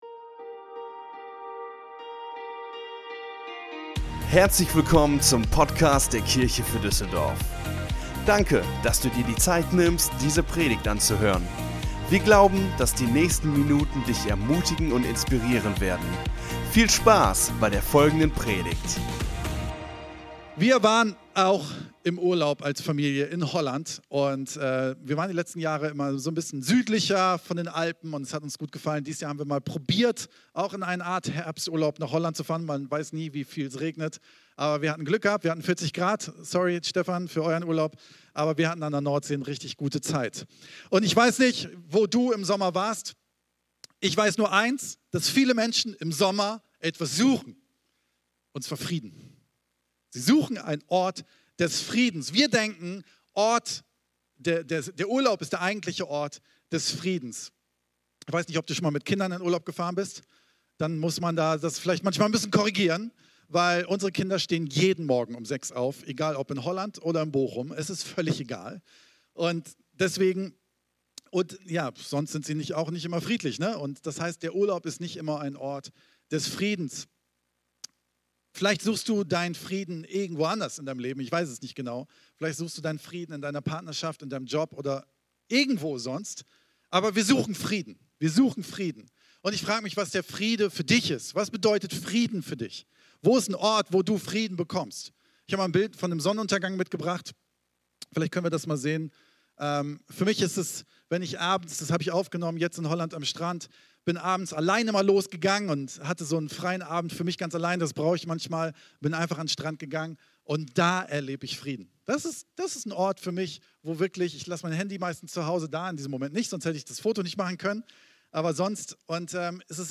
Der dritte Teil unserer Predigtreihe "MIXTAPE" Vier Sonntage - Vier Pastoren Thema: Die fünf Steine Folge direkt herunterladen